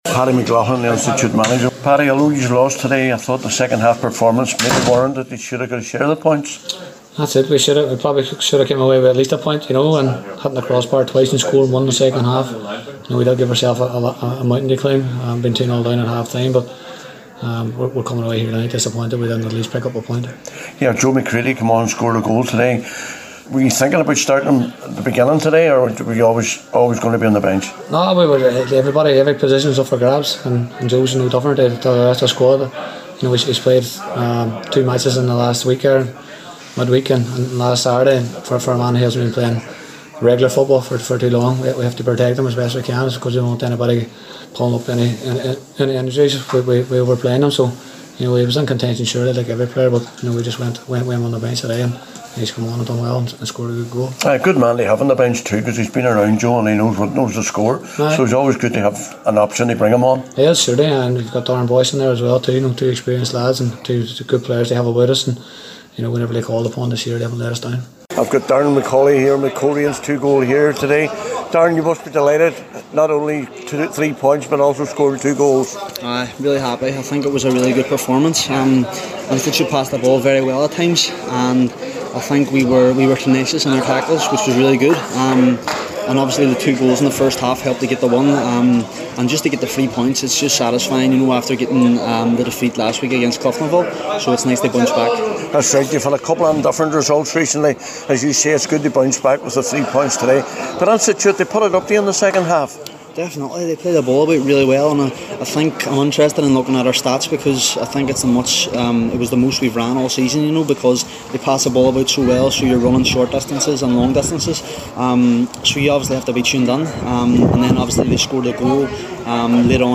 After the match